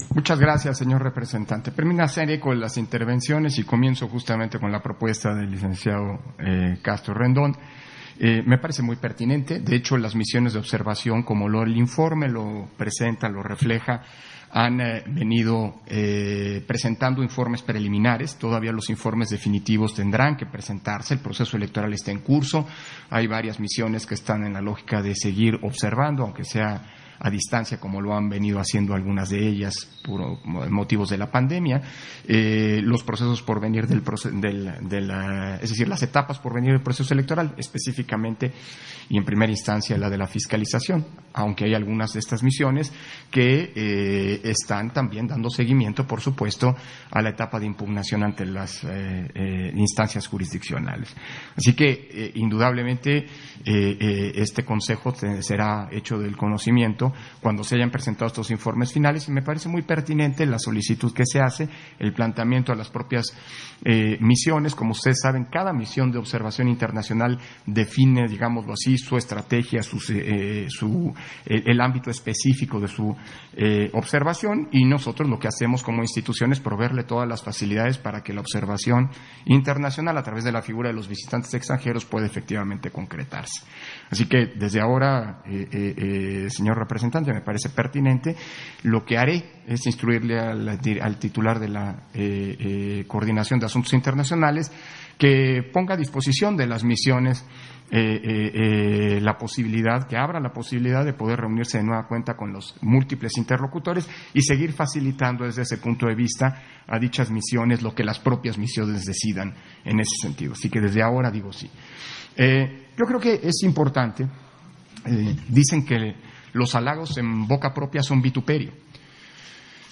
Intervención de Lorenzo Córdova, en Sesión Ordinaria, relativo al décimo informe que presenta la Coordinación de Asuntos Internacionales sobre actividades de atención a visitantes extranjeros en las Elecciones 2021